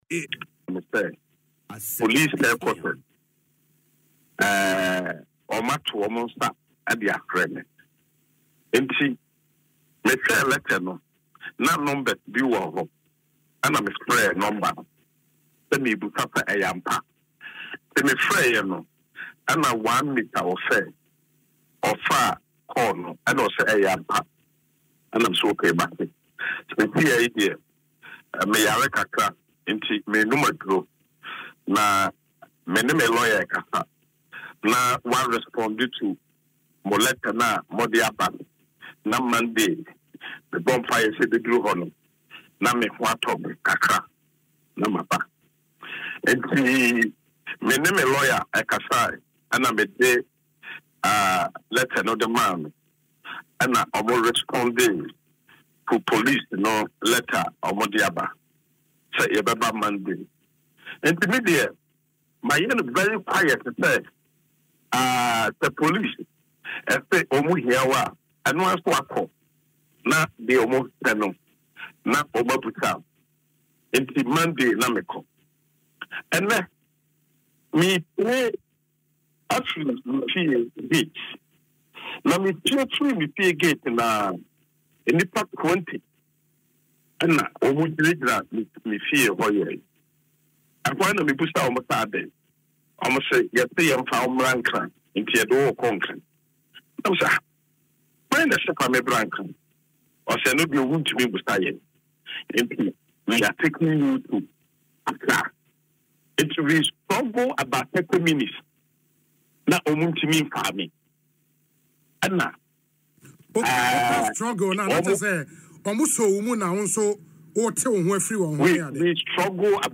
Speaking in an interview on Asempa FM’s Ekosii Sen show, the outspoken politician questioned the basis for the raid.